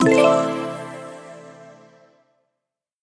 fanfare.mp3